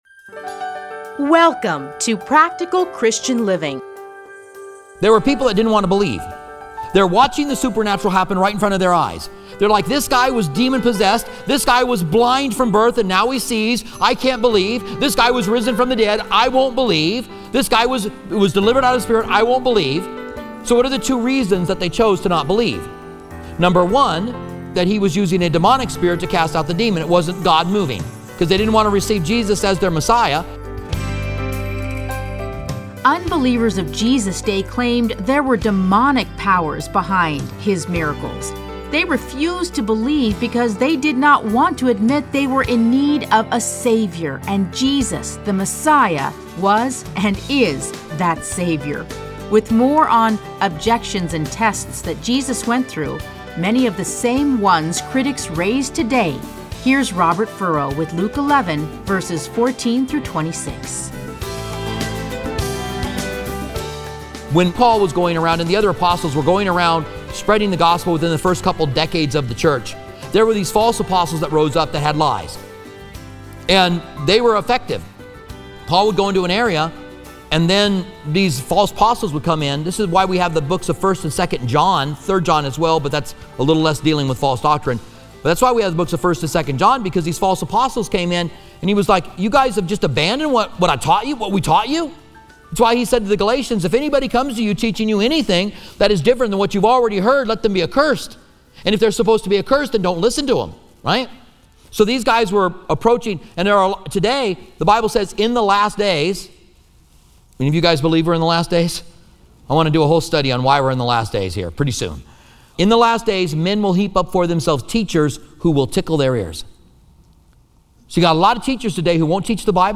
Listen to a teaching from Luke Luke 11:14-26.